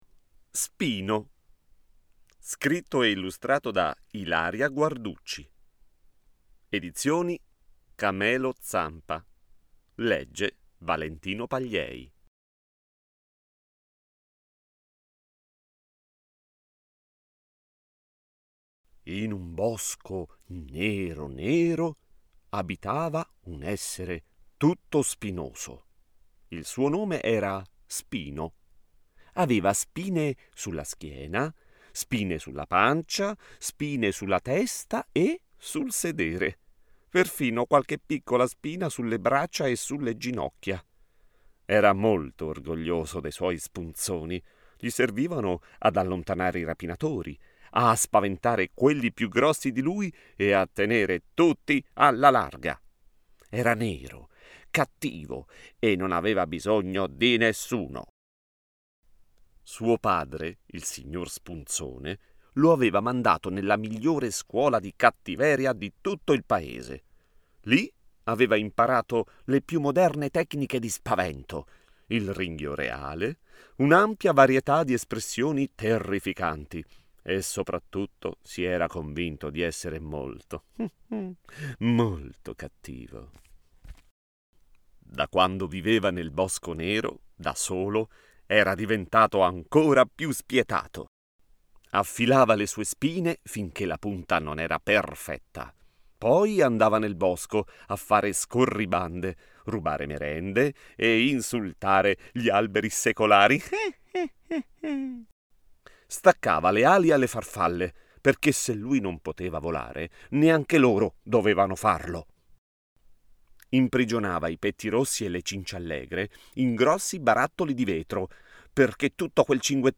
senza tappeto sonoro
Spino-senza-tappeto-sonoro.mp3